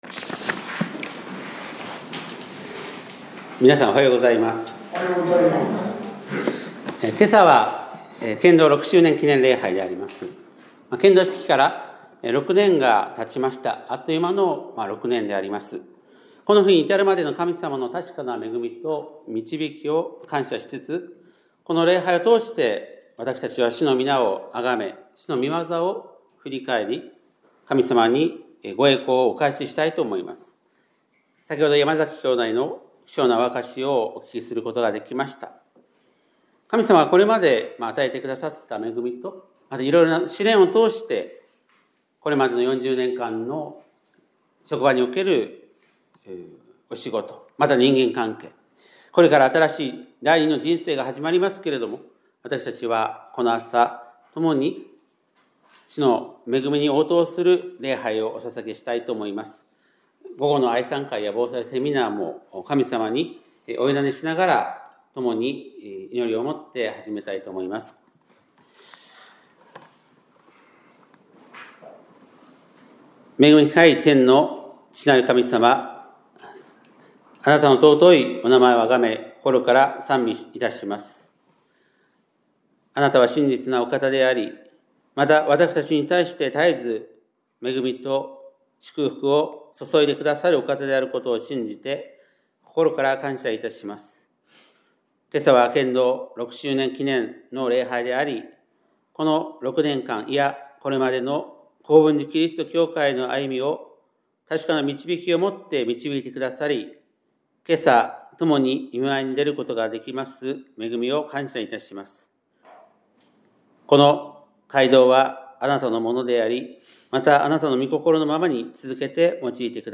2026年2月22日（日）礼拝メッセージ - 香川県高松市のキリスト教会
2026年2月22日（日）礼拝メッセージ